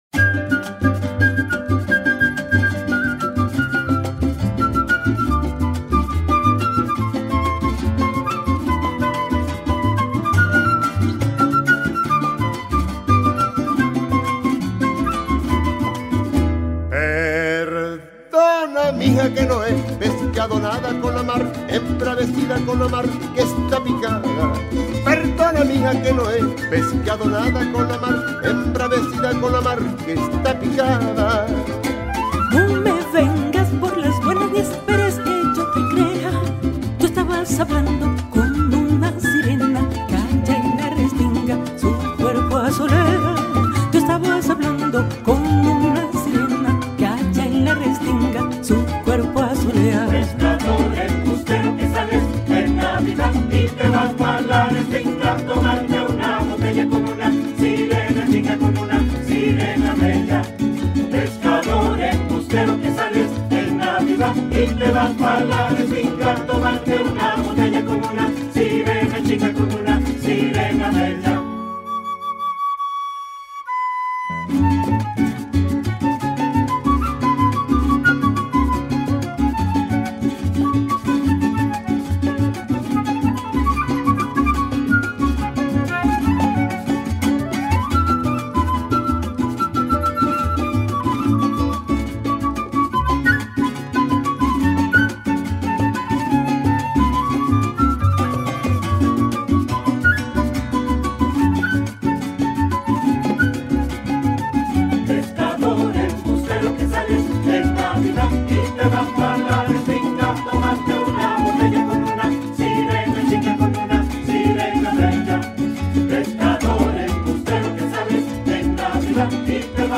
gaita